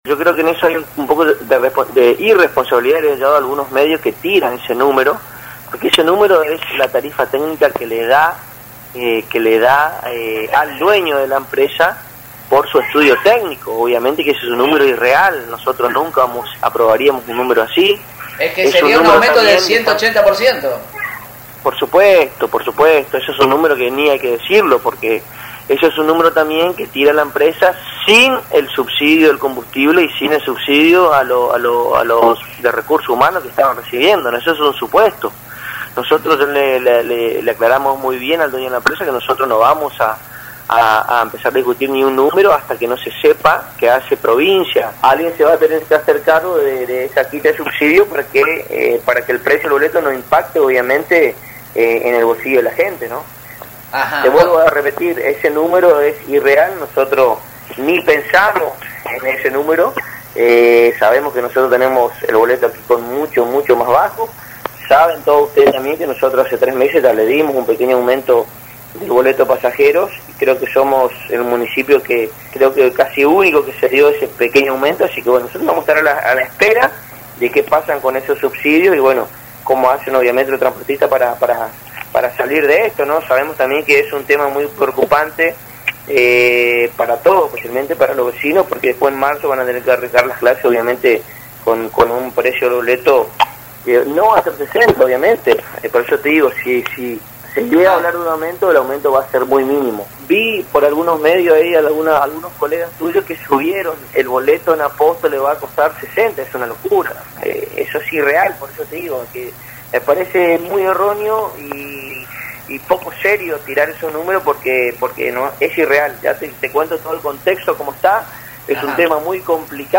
En la charla quedó explícita desde el concejo el no a un aumento del S.U.P. y aseguró el entrevistado, llevando tranquilidad a la comunidad, que el aumento, de existir, será mínimo, teniendo en cuenta el ya otorgado hace algunos meses.